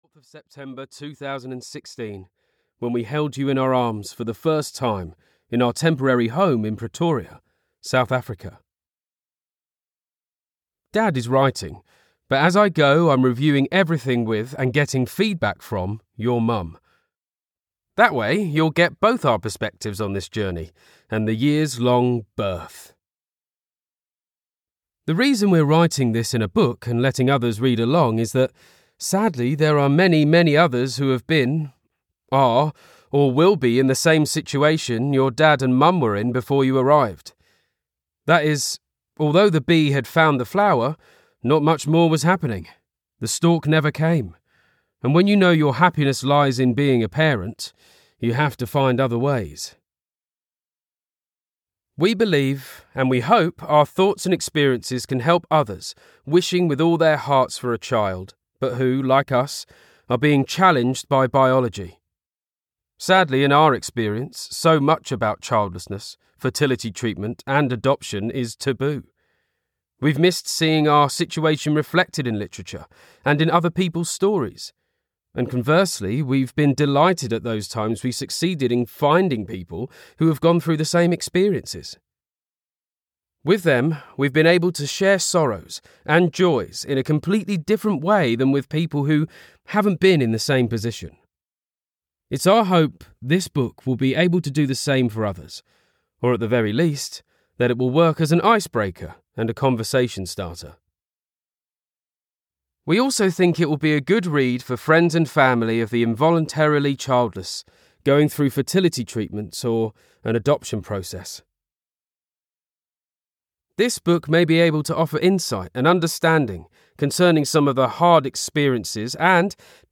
Dear Zoe Ukhona: a Journey through Infertility and Adoption (EN) audiokniha
Ukázka z knihy